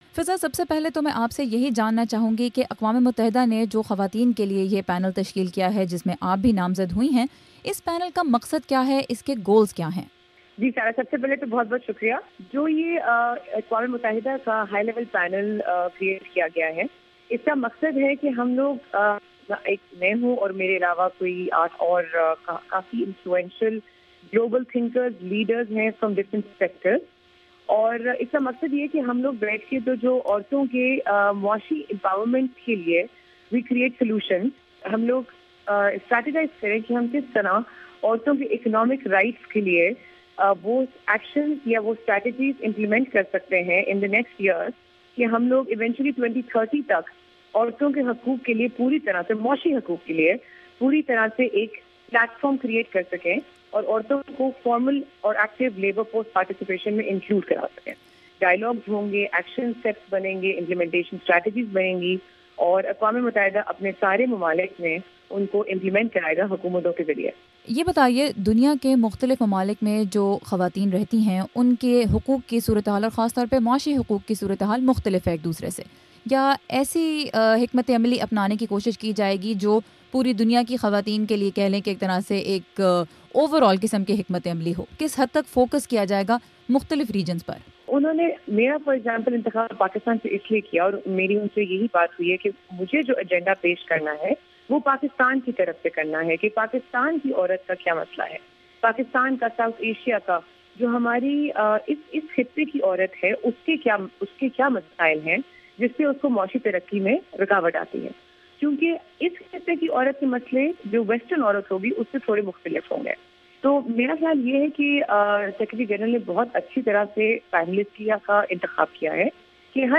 انٹرویو